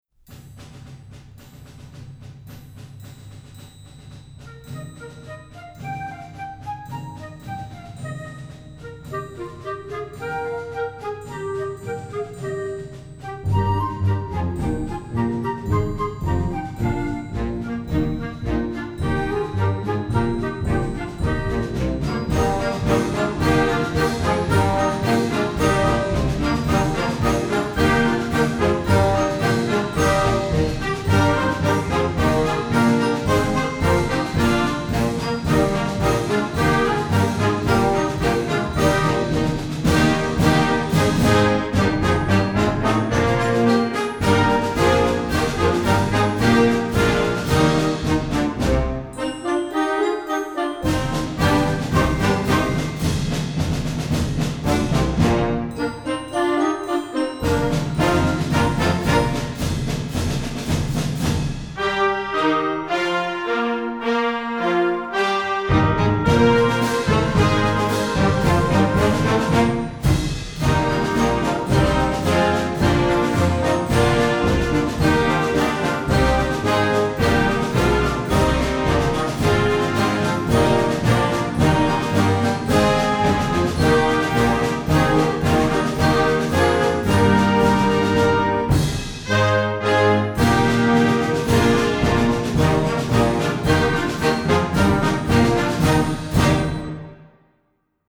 Band → Concert Marches
Voicing: Concert March